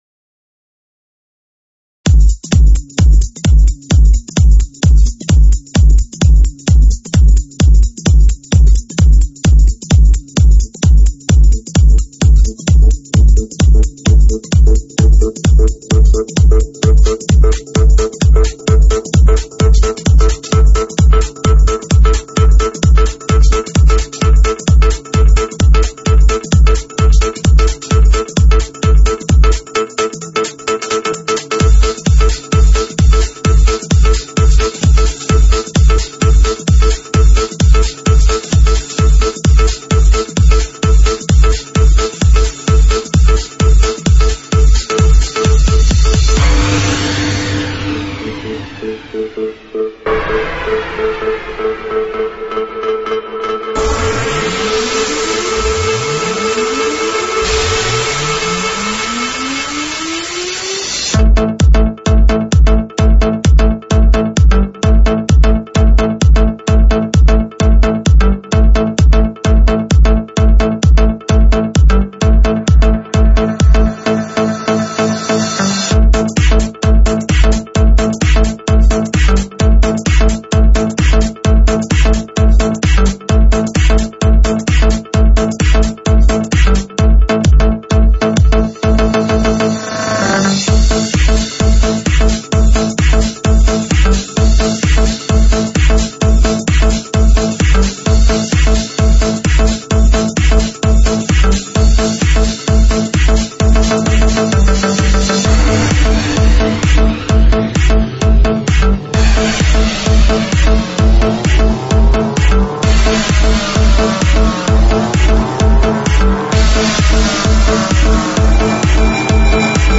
Просто бомба хит (стиль electro house 2007)